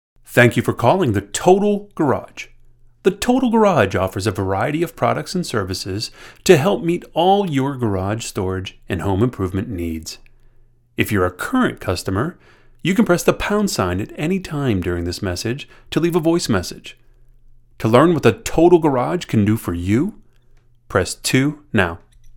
Telephone Samplier
Sample-Demo-Telephone-Total-Garage.mp3